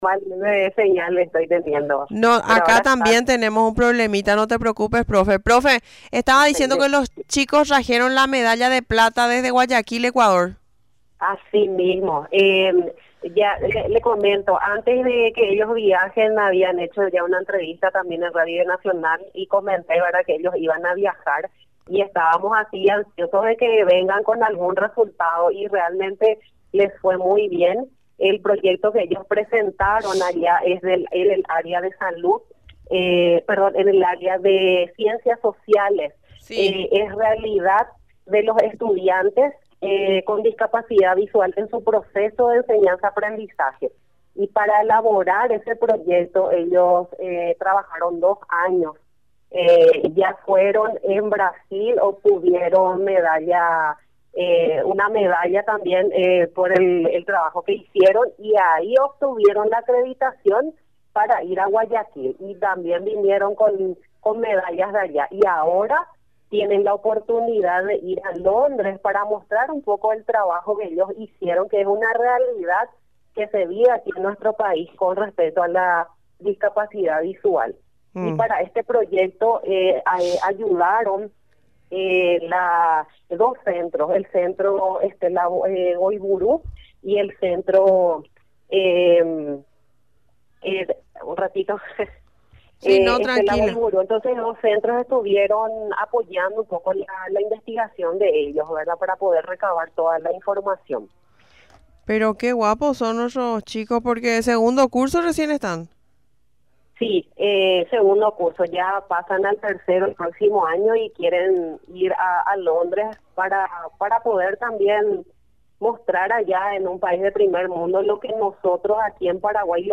Finalmente, en declaraciones en Radio Nacional del Paraguay, resaltó que este logro obtenido, les valió la acreditación para representar a Paraguay en La i-WISE The International – World Innovative Student Expo, en Londres. Afirmó que la competición será en el 2025.